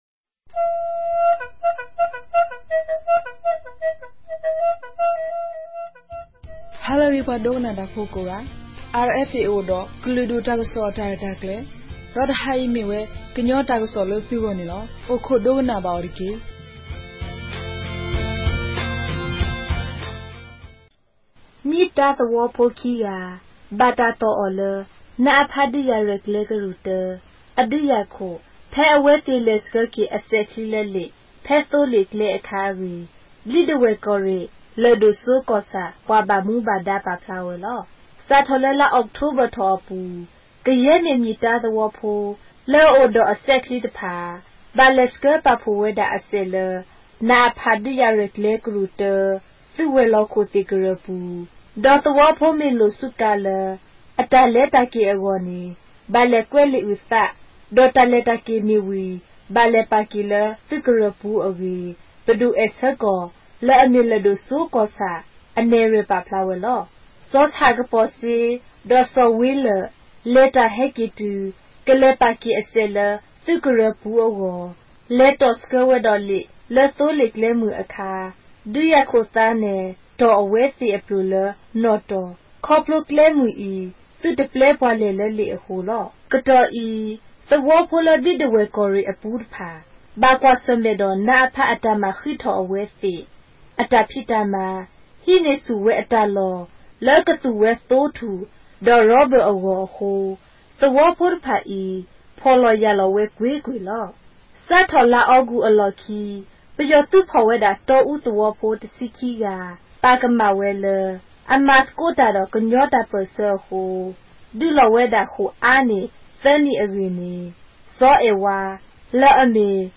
ကရင်ဘာသာ အသံလြင့်အစီအစဉ်မဵား